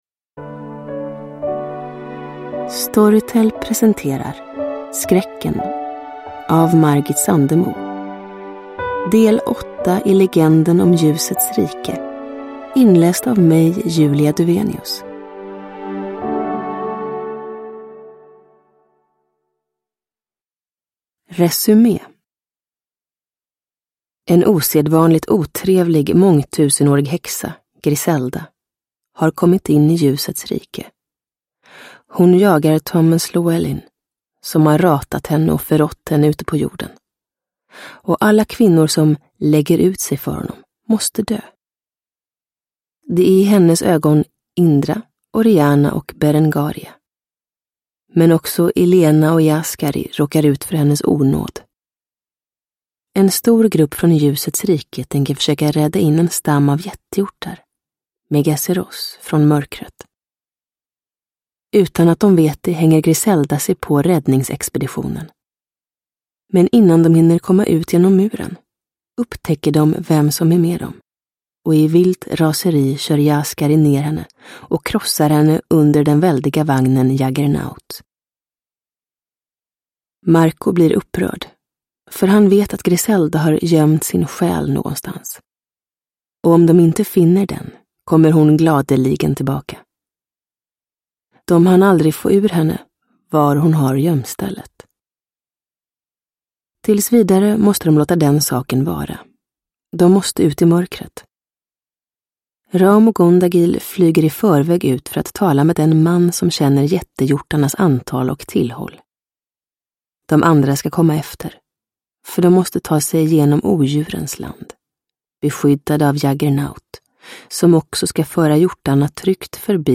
Skräcken – Ljudbok – Laddas ner